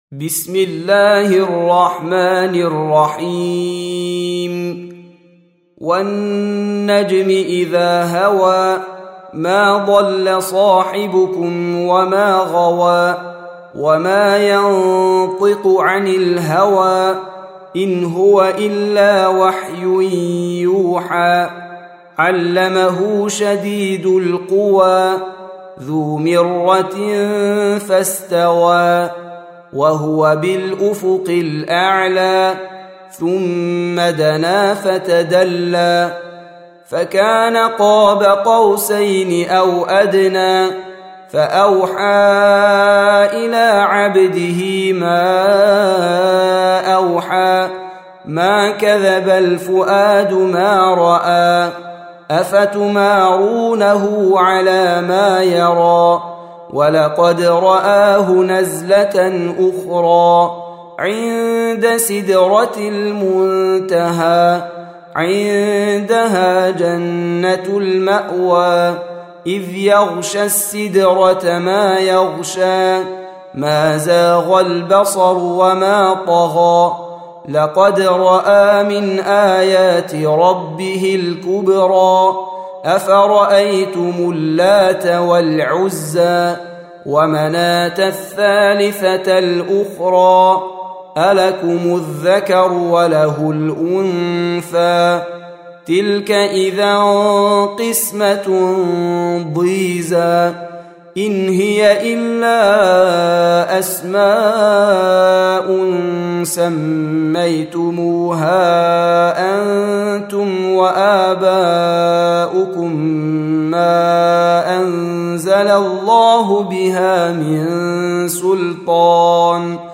53. Surah An-Najm سورة النجم Audio Quran Tarteel Recitation
حفص عن عاصم Hafs for Assem
Surah Repeating تكرار السورة Download Surah حمّل السورة Reciting Murattalah Audio for 53.